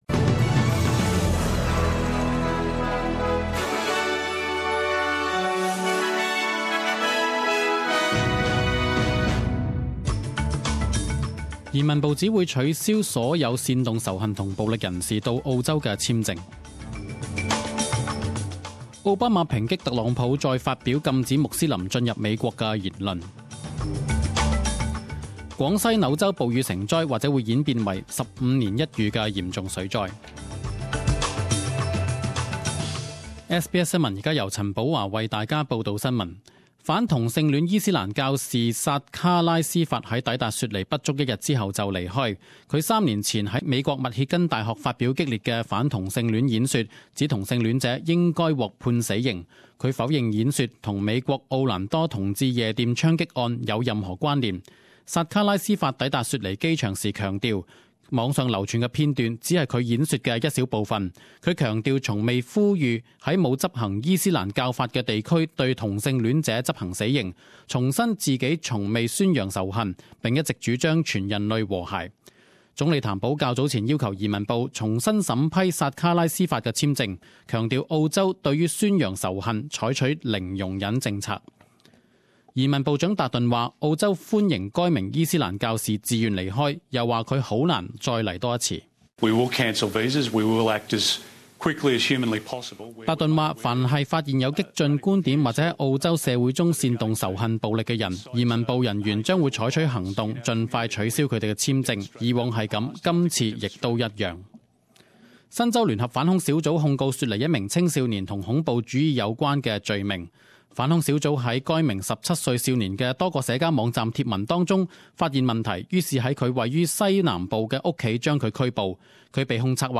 十点钟新闻报导 （六月十五日）